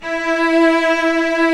Index of /90_sSampleCDs/Roland LCDP13 String Sections/STR_Vcs I/STR_Vcs2 f Slo